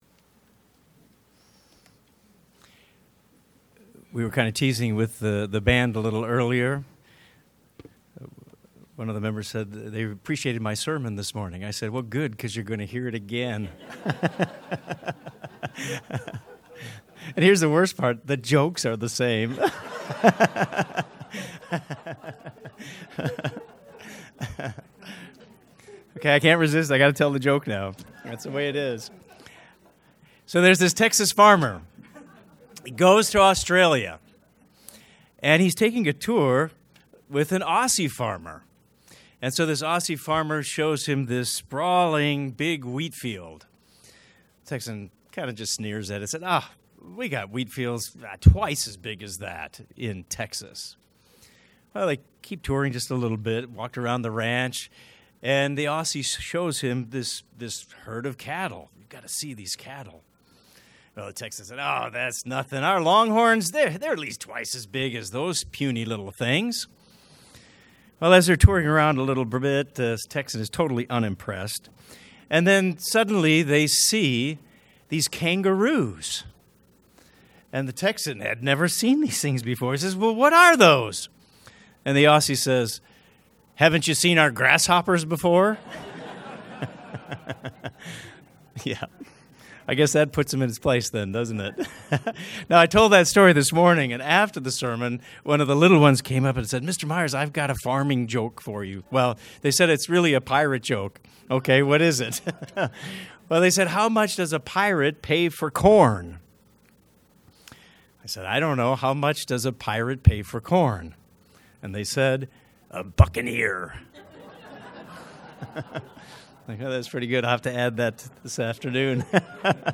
What is the wave sheaf offering and who are the firstfruits? Find out in this sermon!